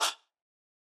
murda chant final.wav